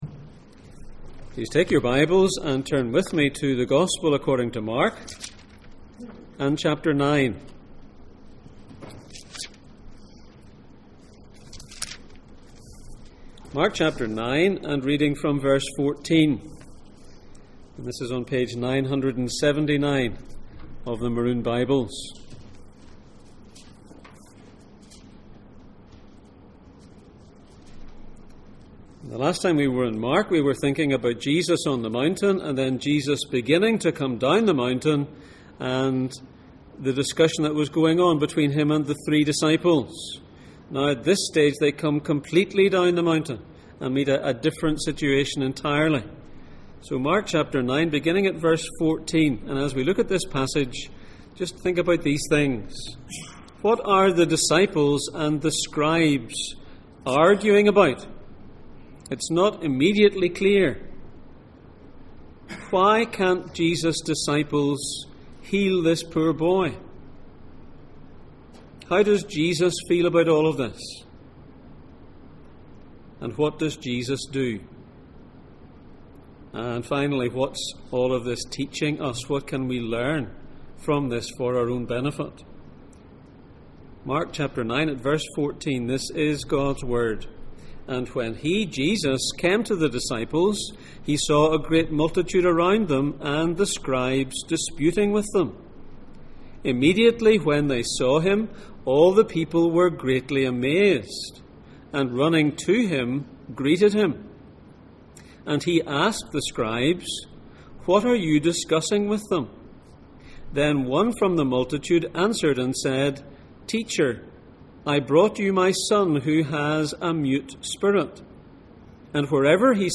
Jesus in Mark Passage: Mark 9:14-29, Acts 26:14-18, Ephesians 2:1-3 Service Type: Sunday Morning